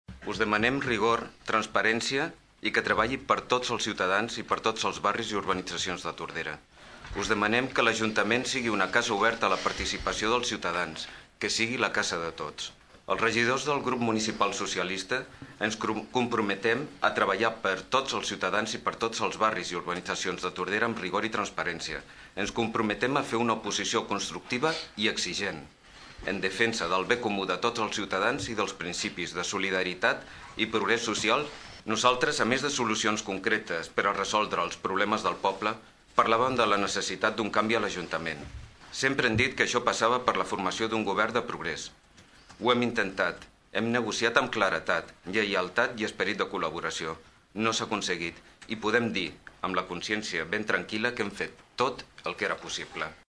L’auditori del Teatre Clavé s’ha omplert de gom a gom durant en Ple de constitució del nou ajuntament.